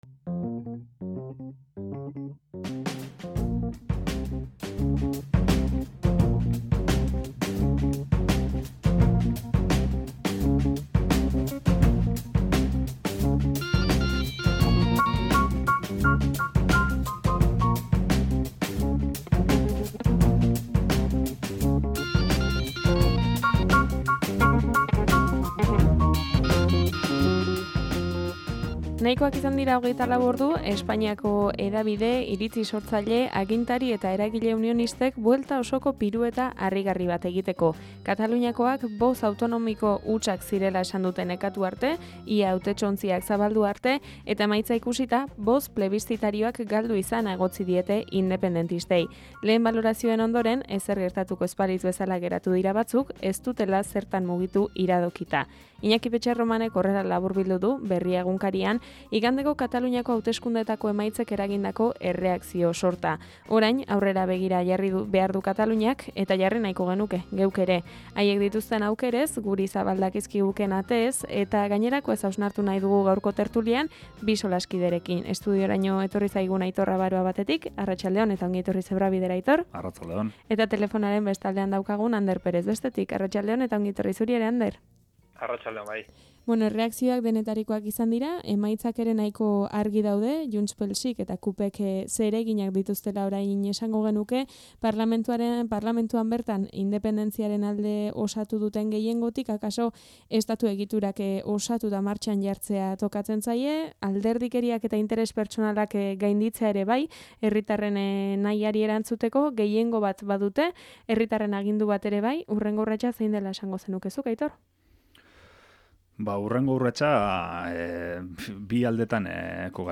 TERTULIA: Kataluniako plebiszitu hauteskundeek gurean izan dezaketen eraginaz